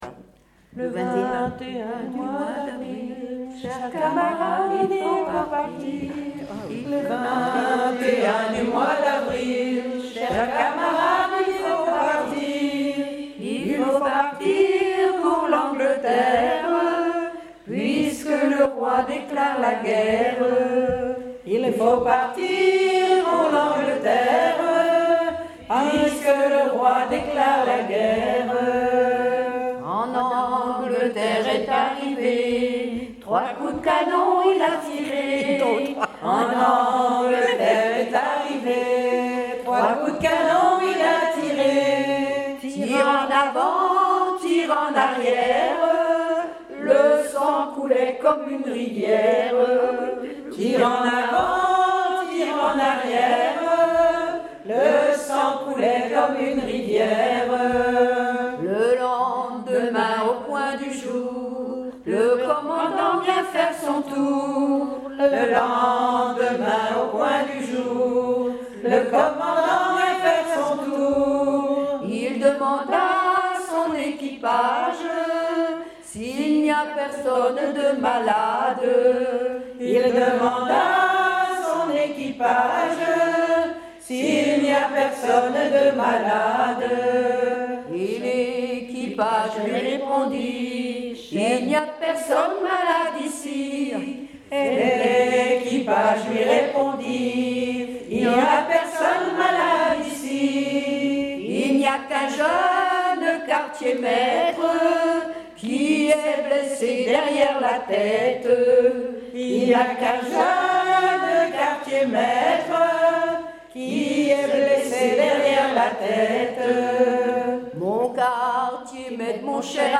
Chanté collectivement lors d'une veillée le 22 septembre 2012
enregistrement de veillées en chansons